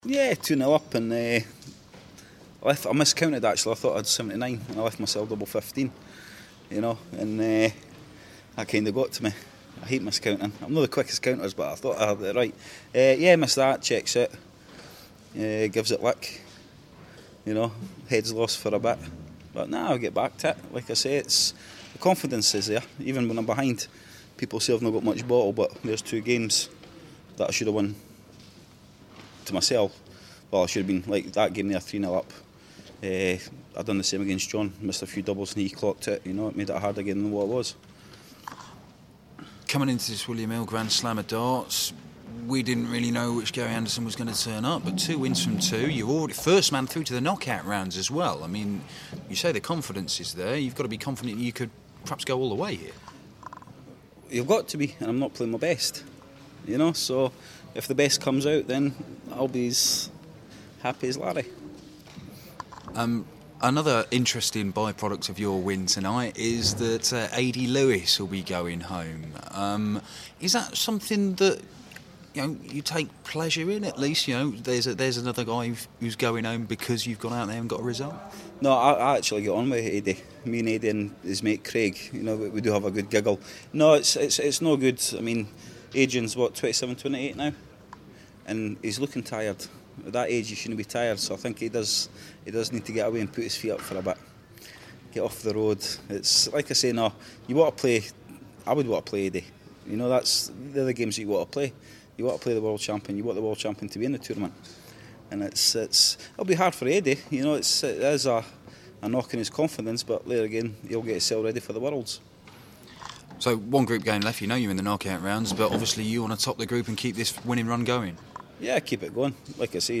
William Hill GSOD - Anderson Interview (2nd game)